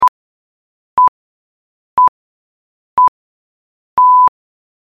جلوه های صوتی
دانلود صدای تلویزیون 3 از ساعد نیوز با لینک مستقیم و کیفیت بالا